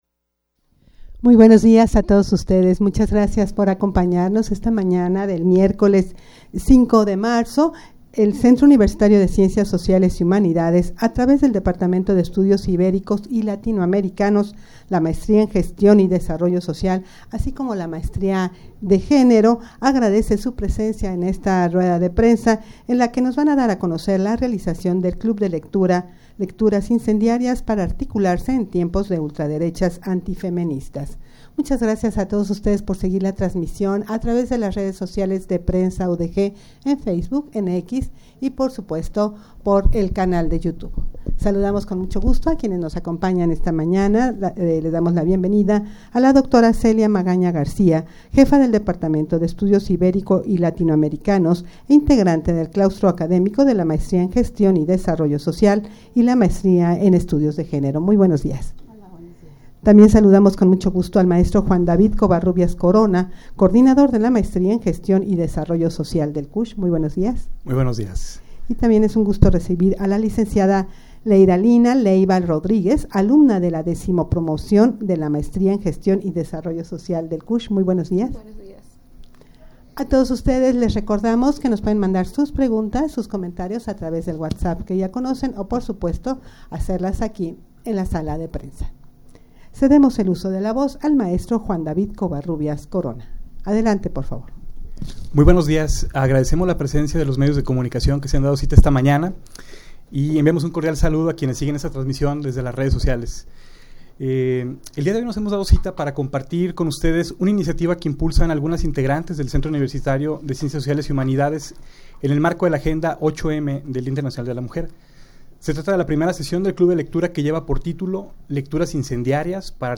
rueda-de-prensa-para-dar-a-conocer-la-realizacion-del-club-de-lectura.mp3